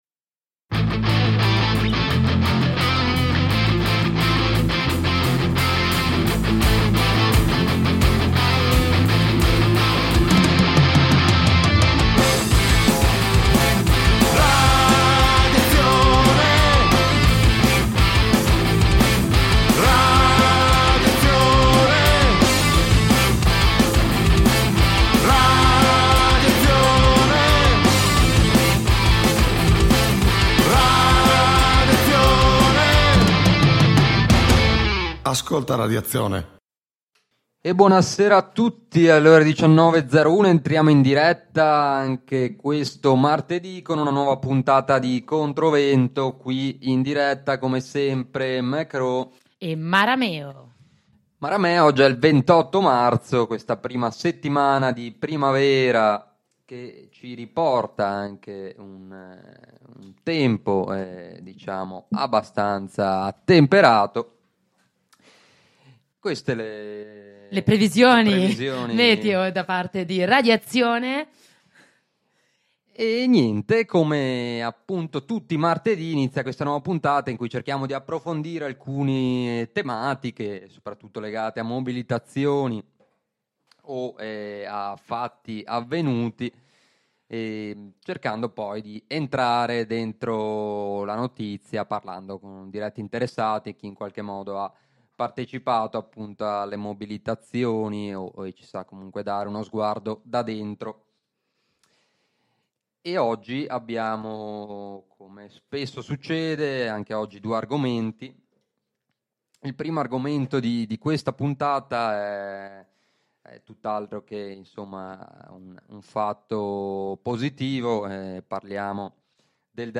In questa puntata di ControVento ci colleghiamo con un compagno greco per una riflessione a freddo sul deragliamento di un mese fa vicino Tempes costato la vita a 57 persone e che ha provocato forti proteste per settimane in tutta la Grecia. Di fronte alla derubricazione come tragica fatalità dovuta ad errore umano, il popolo Greco ha denunciato con determinazione il fatto come una strage di stato dovuta al continuo taglio di finanziamenti al settore dei trasporti, alla non messa in sicurezza e alla obsolescenza del sistema ferroviario ellenico.